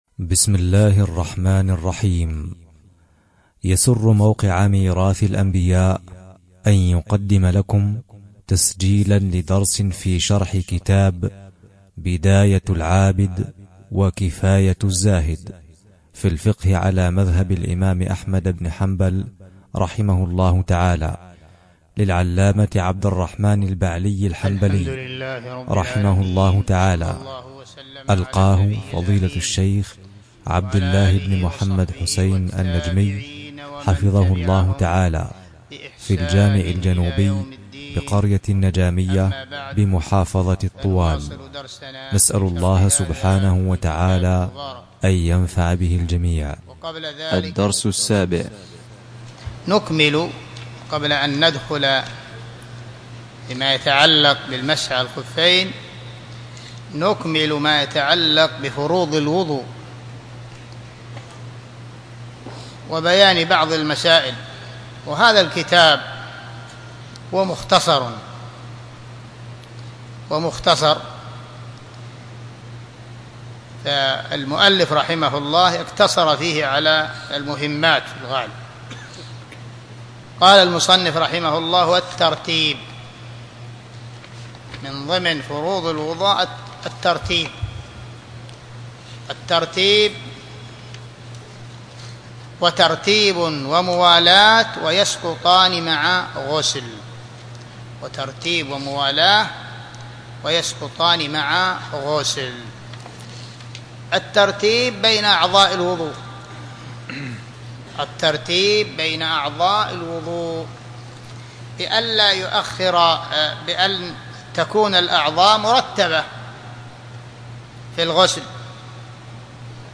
الدرس 7